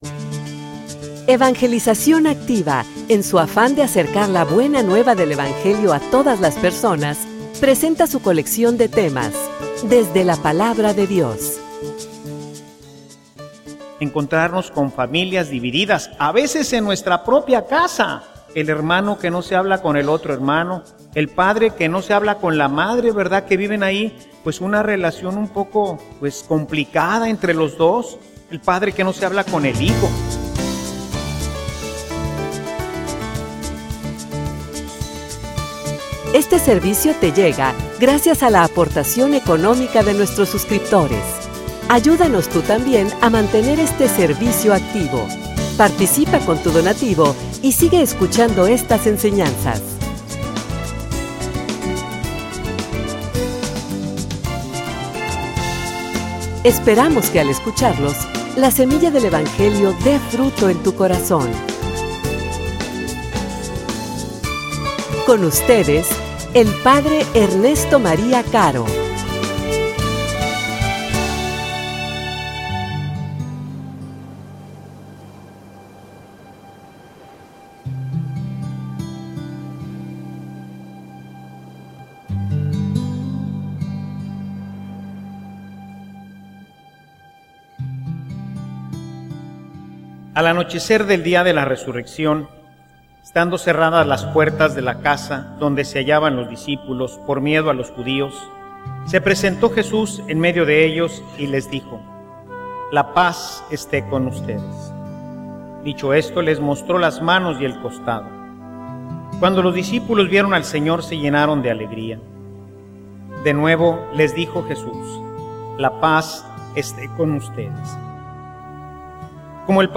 homilia_Equipados_para_la_mision.mp3